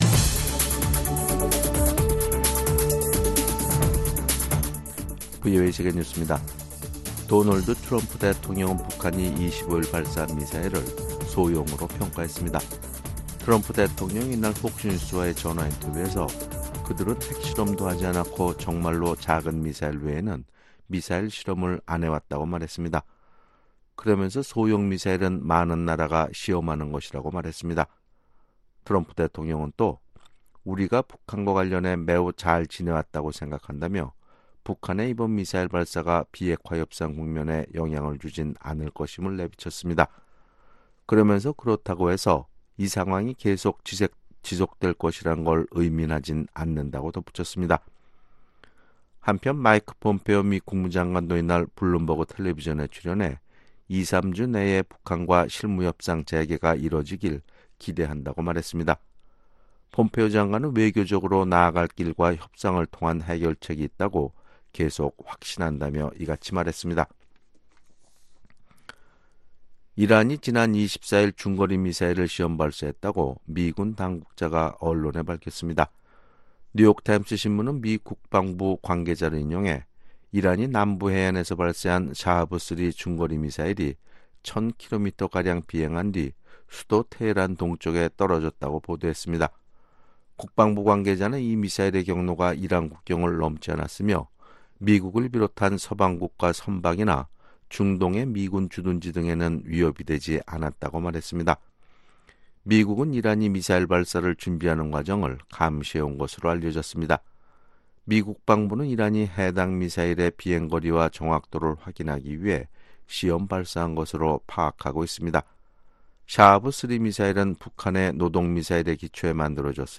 VOA 한국어 아침 뉴스 프로그램 '워싱턴 뉴스 광장' 2019년 7월 27일 방송입니다. 트럼프 미국 대통령이 북한이 25일 발사한 단거리 탄도미사일을 ‘소형’ 미사일 시험일 뿐이었다며, 미-북 비핵화 협상에 영향을 미치지 않을 것이라고 강조했습니다.